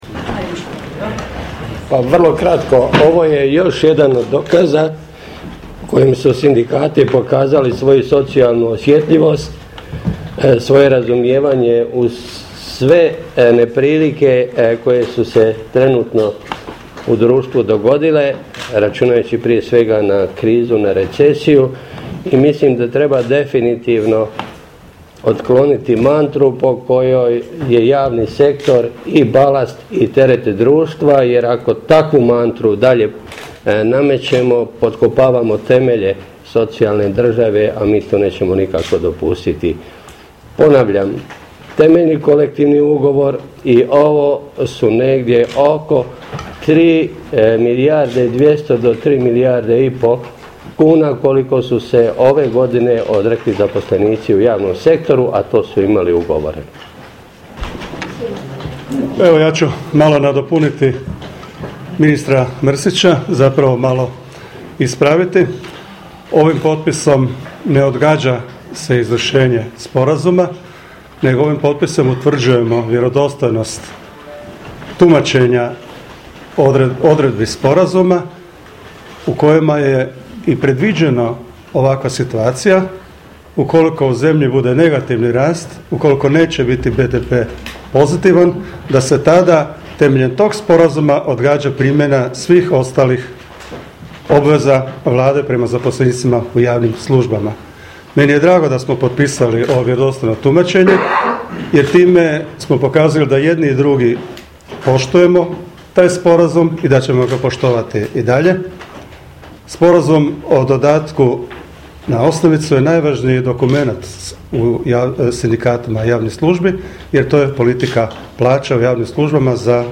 izjave predstavnika sindikata javnih službi